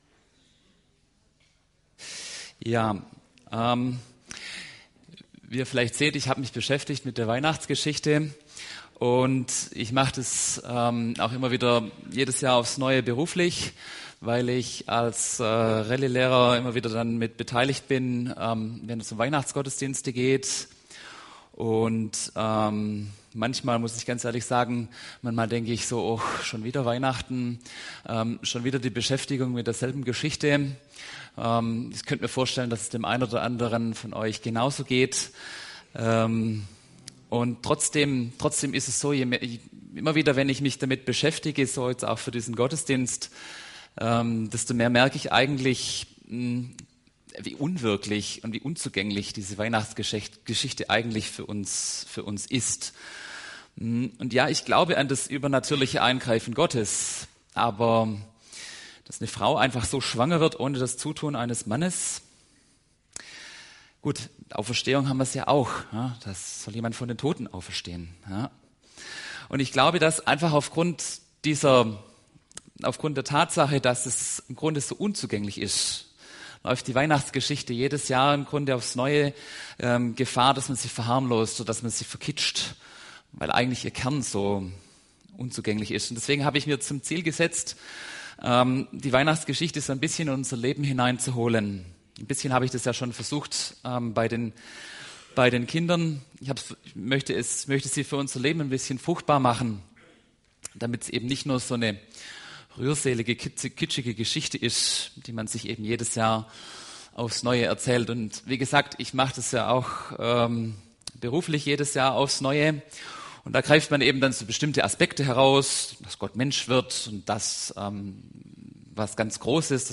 Kindergottesdienst